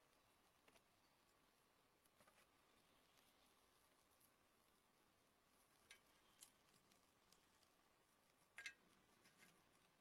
Kling-Audio-Eval / Natural sounds /Fire /audio /10051.wav